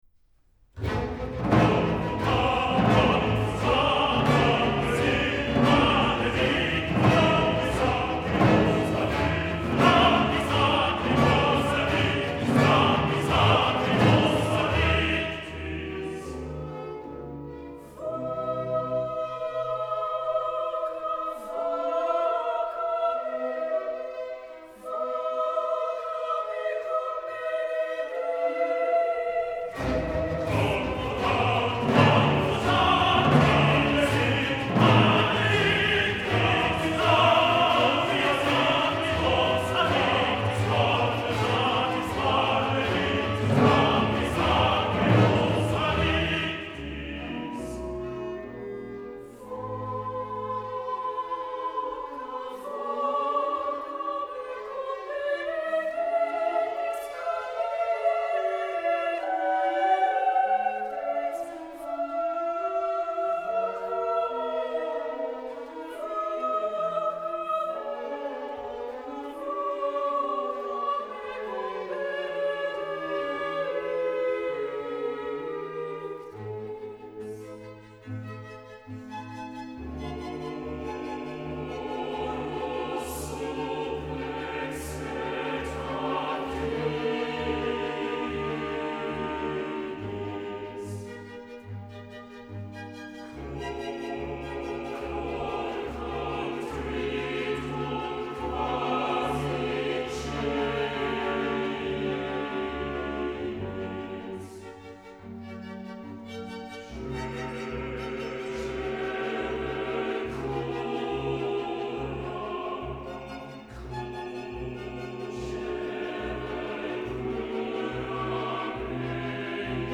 Барочное-лирическое-эпическое.